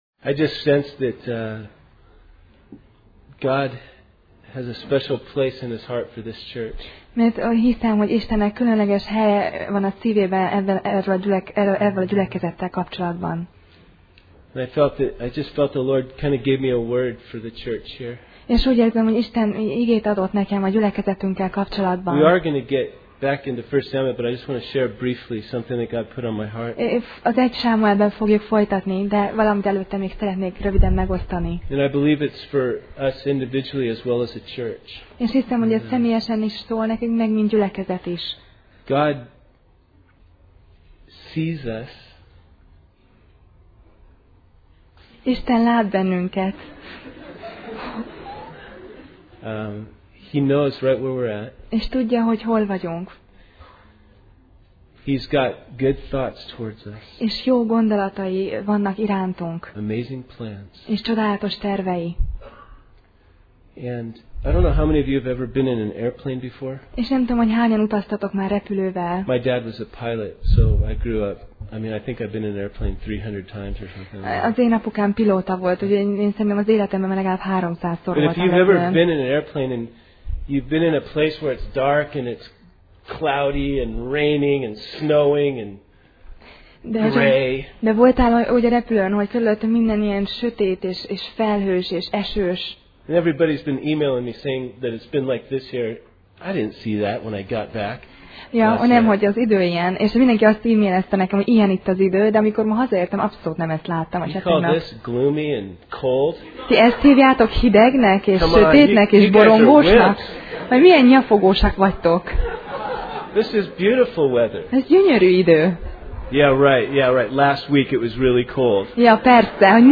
1Sámuel Passage: 1Sámuel (1Samuel) 25:1-13 Alkalom: Szerda Este « Róma